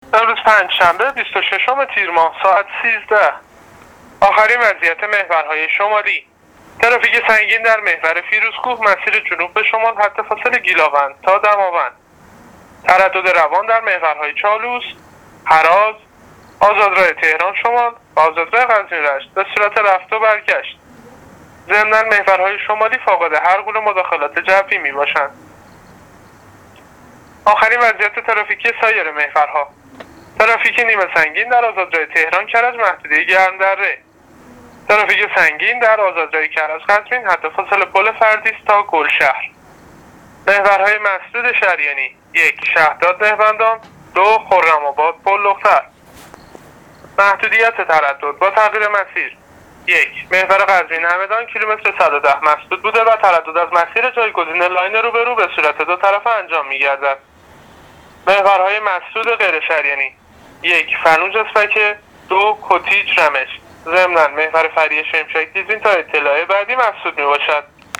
گزارش رادیواینترنتی از وضعیت ترافیکی جاده‌ها تا ساعت ۱۳ پنجشنبه ۲۶ تیر